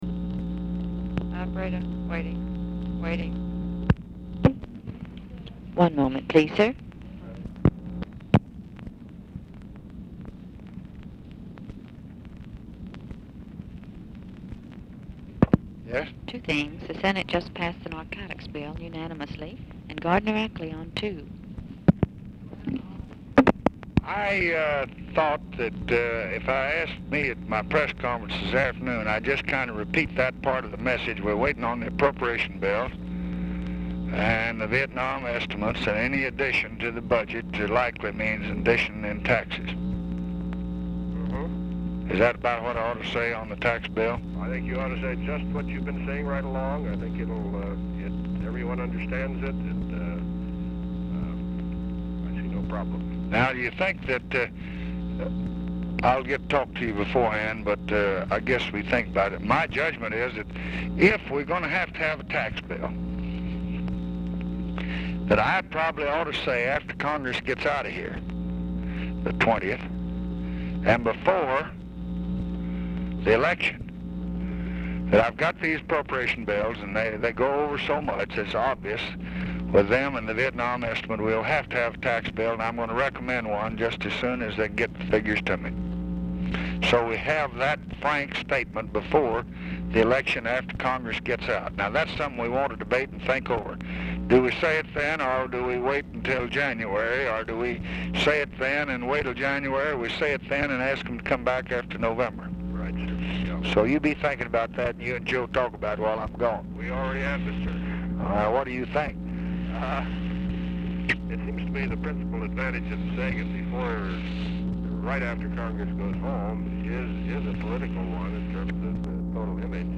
Telephone conversation # 10928, sound recording, LBJ and GARDNER ACKLEY, 10/6/1966, 12:50PM | Discover LBJ
ACKLEY ON HOLD 0:22; OFFICE SECRETARY TELLS LBJ THAT SENATE HAS UNANIMOUSLY PASSED THE NARCOTICS BILL, THEN ANNOUNCES GARDNER ACKLEY'S CALL
Format Dictation belt
Specific Item Type Telephone conversation